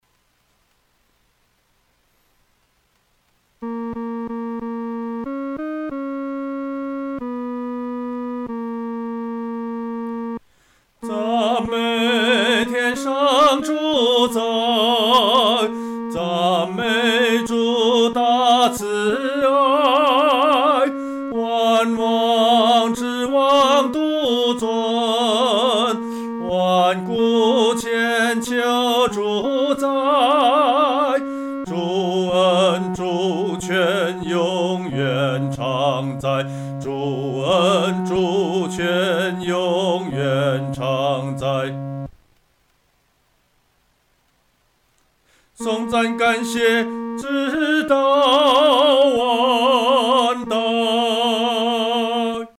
独唱（第三声）
曲调欢快、明朗